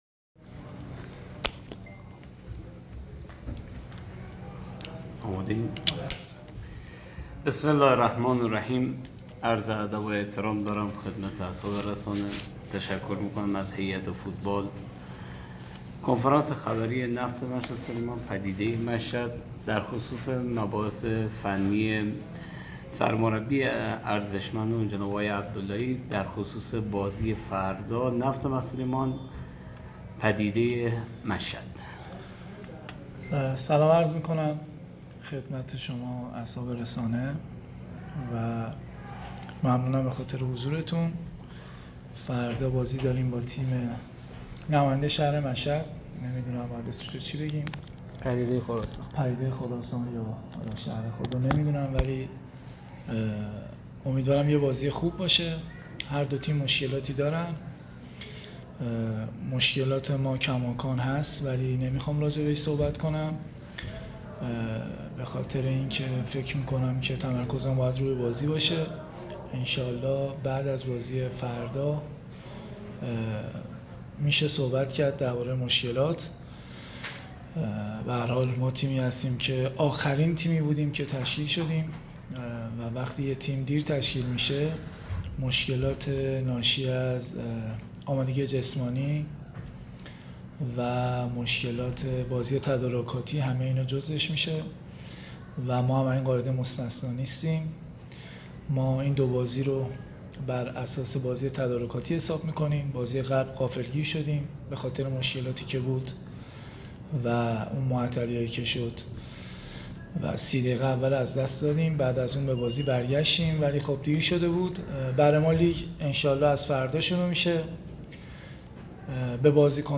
برچسب ها: کنفرانس خبری ، پدیده خراسان ، نفت مسجد سلیمان